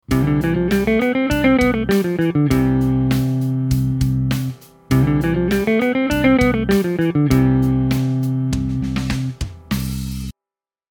When improvising we can play the C major scale over the C major chord.
C Major Scale
majorscale-1.mp3